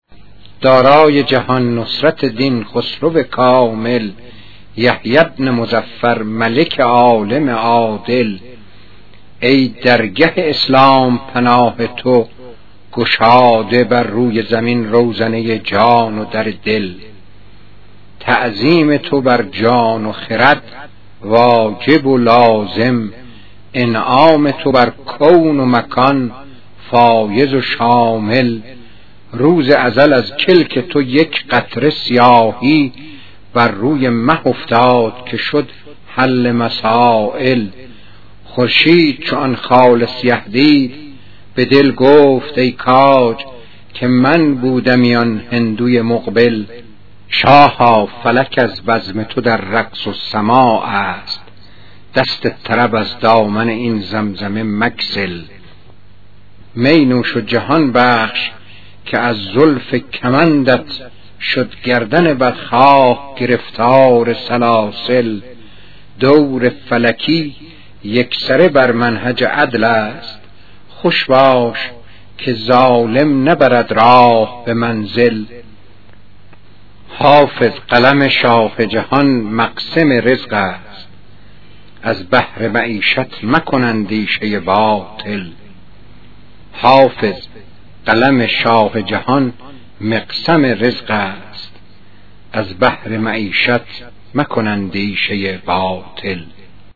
🎵 پخش صوتی غزل با صدای موسوی گرمارودی: